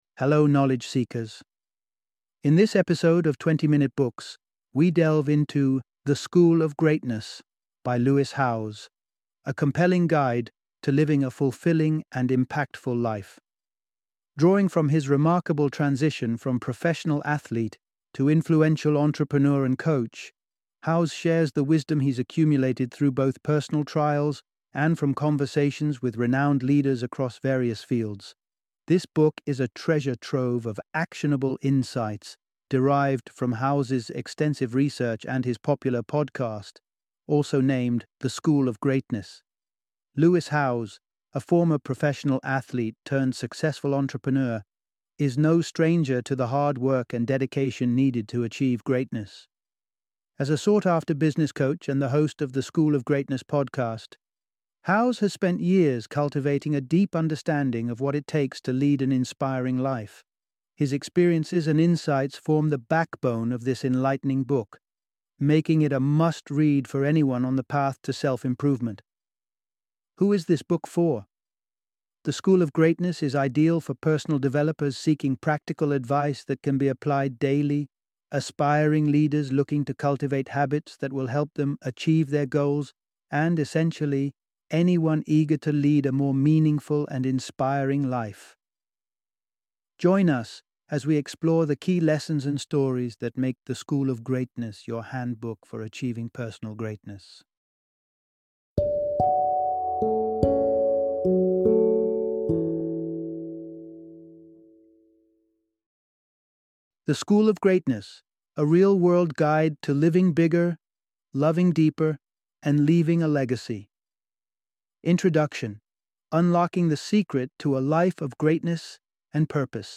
The School of Greatness - Audiobook Summary